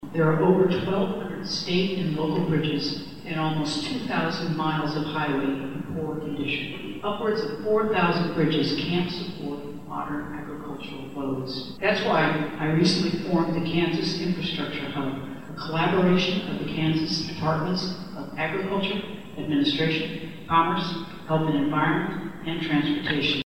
Governor Kelly addresses the 2022 Kansas Ag Summit in Manhattan